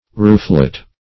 Rooflet \Roof"let\, n. A small roof, covering, or shelter.